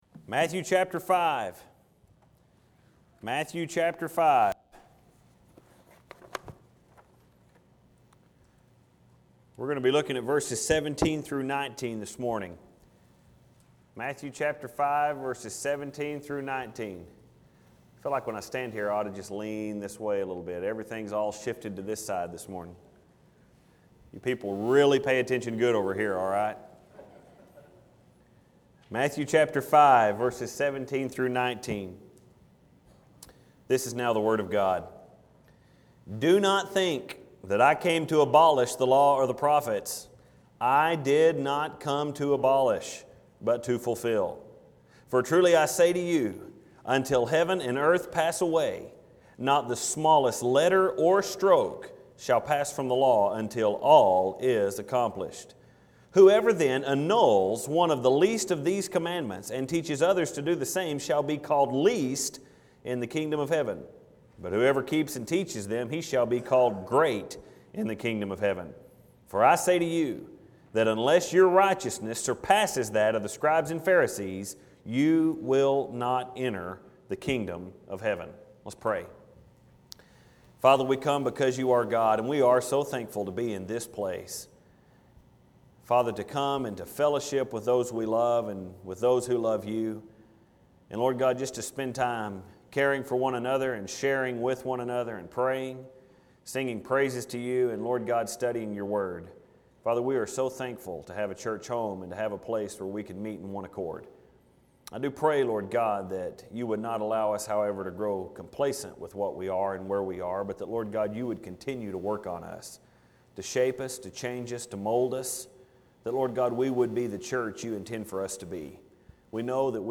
THIS SERMON IS VERY CONVICTING.